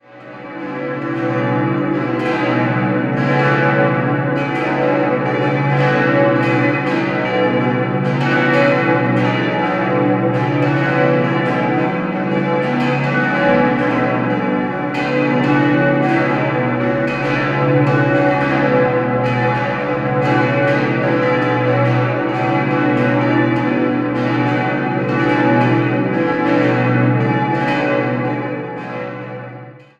5-stimmiges Geläut: h°-cis'-dis'-fis'-gis'